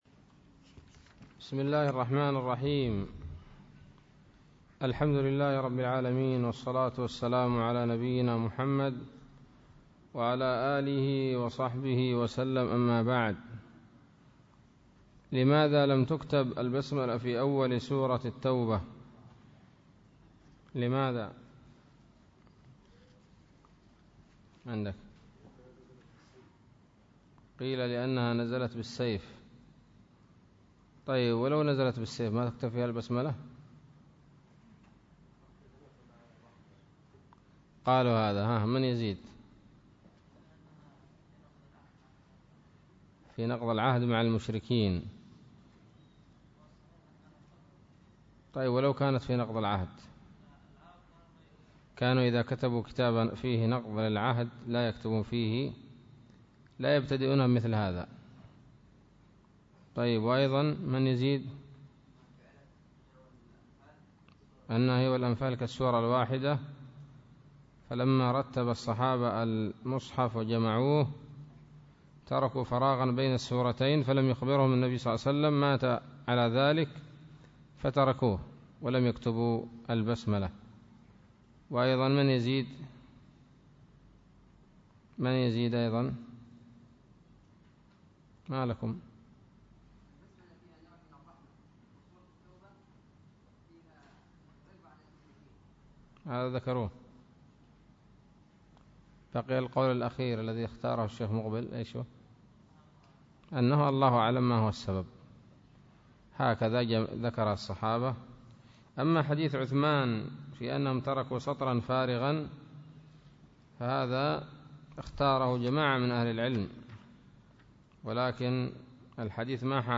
الدرس الثاني من سورة التوبة من تفسير ابن كثير رحمه الله تعالى